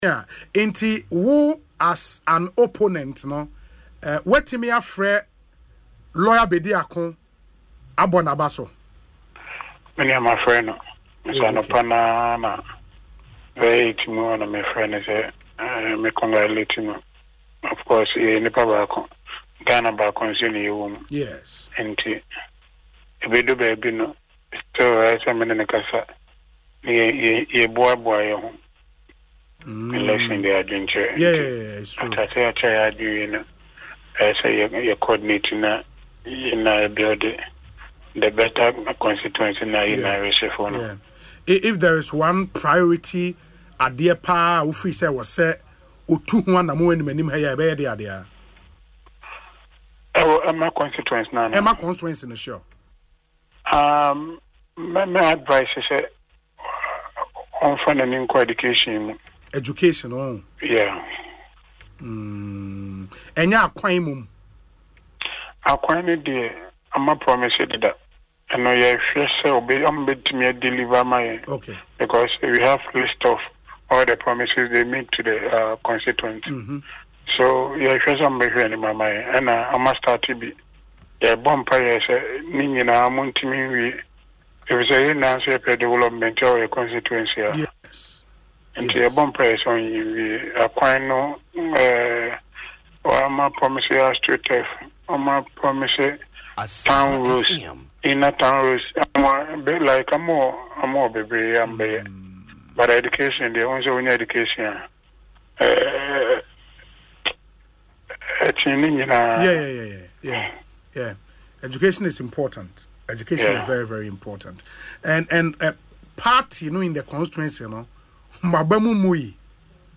Speaking in an interview on Asempa FM’s Ekosii Sen on Wednesday